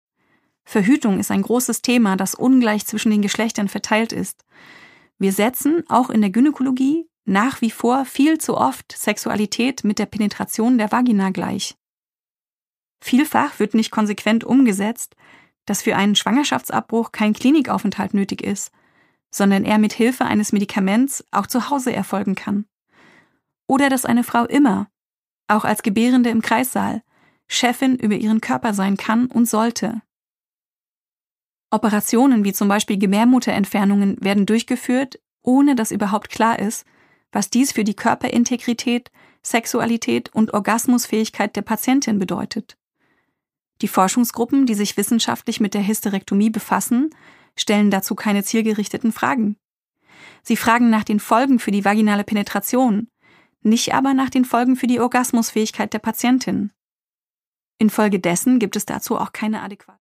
Produkttyp: Hörbuch-Download
Mit einem Vorwort gelesen von der Autorin.